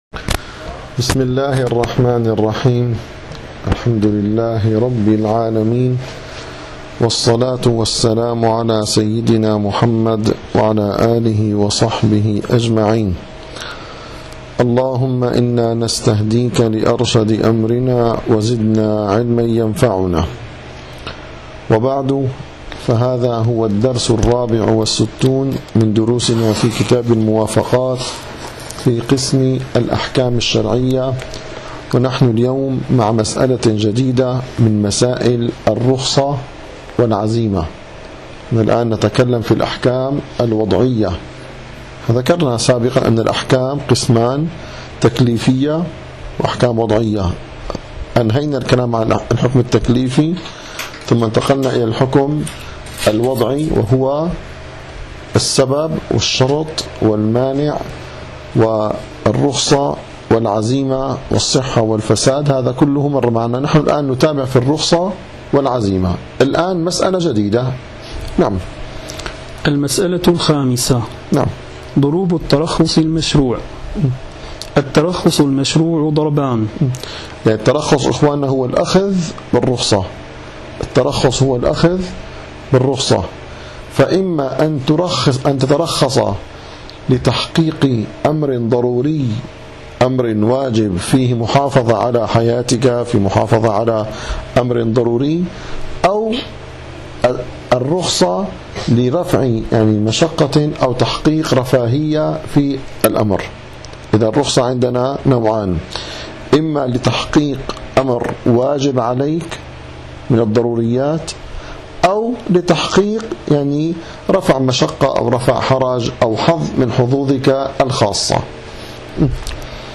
- الدروس العلمية - شرح كتاب الموافقات للشاطبي - 64- المسألة الخامسة ضروب الترخص المشروع